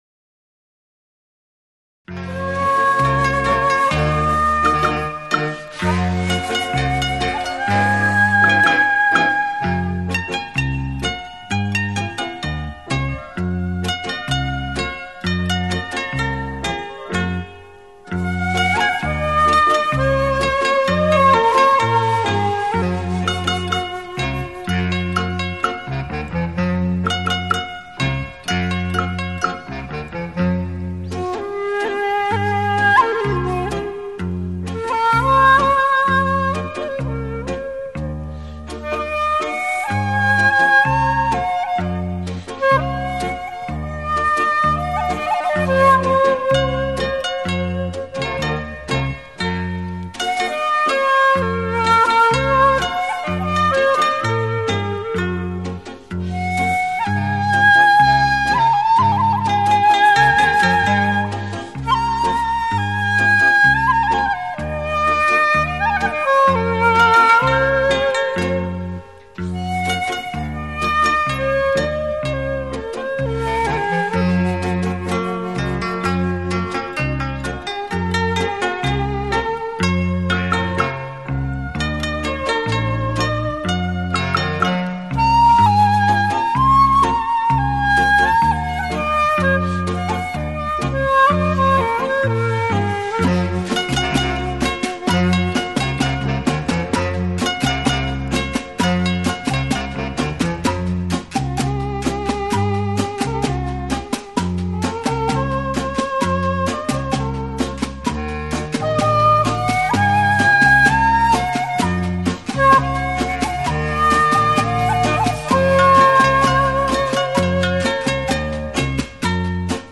尺八